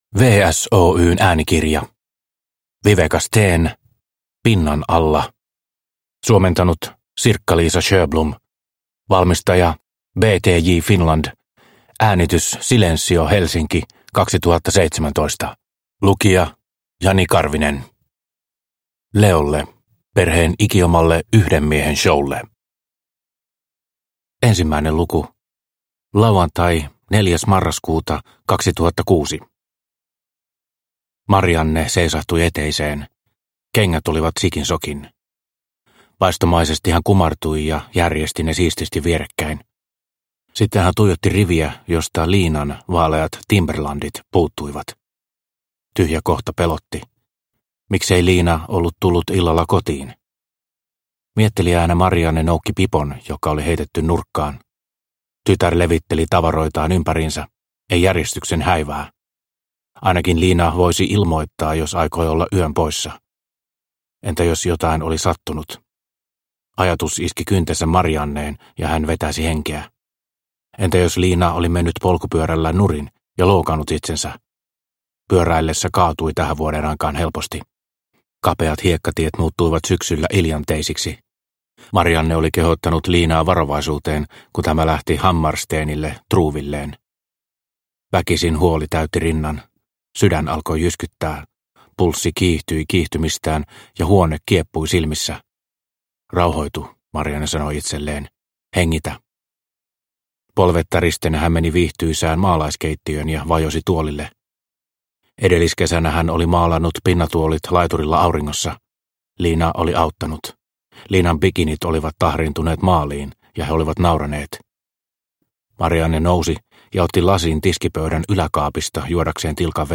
Pinnan alla – Ljudbok – Laddas ner